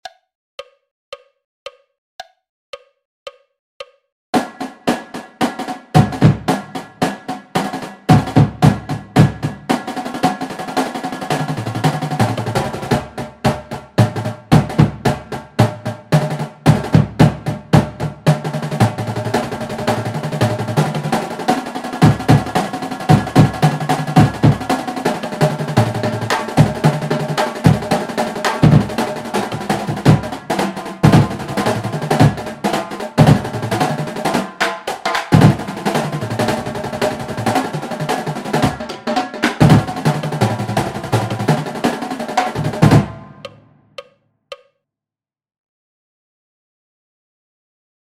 Snare Exercises
Pear (Paradiddles) /